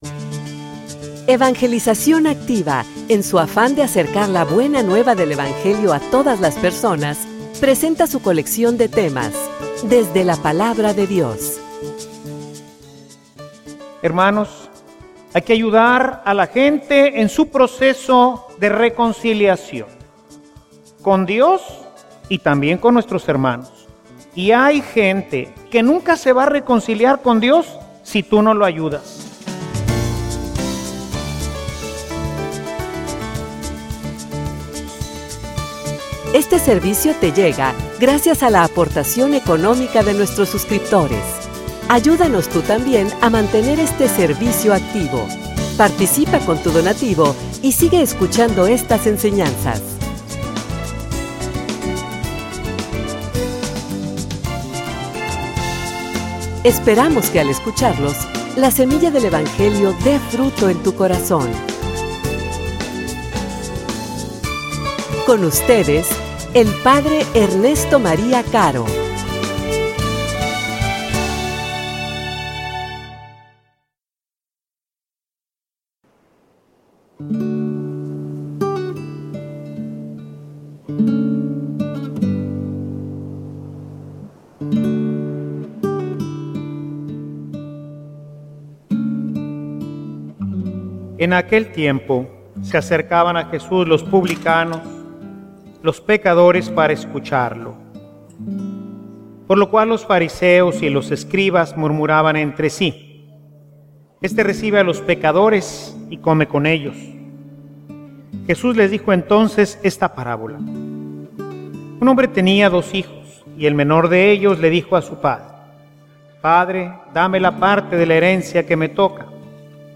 homilia_Agentes_de_reconciliacion.mp3